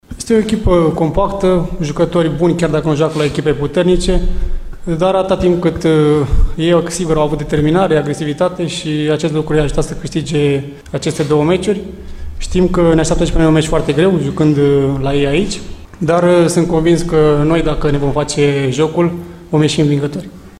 Bancu a vorbit, de asemenea, despre adversar și, în general jocul de la Erevan: